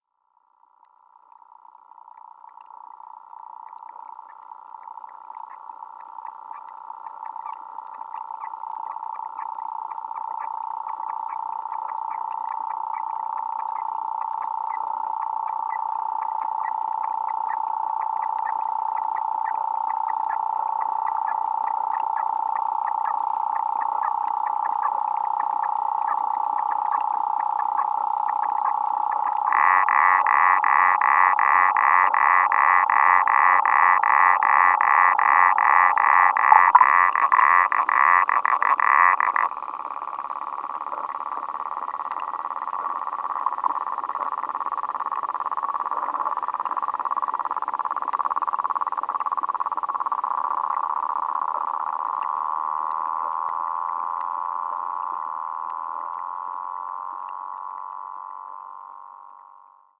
Another consideration is that no mechanical devices were operating on the Fen during the period that these recordings were made. The recordings are not contaminated by any electrical interference. Other than an occasional overhead aircraft, no other sounds from above the water are present in the recordings.
Each of these water insects are known to produce sound through a process called stridulation.
The recordings here were mostly done with hydrophones placed closed to the insect subjects, and apart from the odd sound of aircraft this recording features just recordings of the insects in their natural habituate.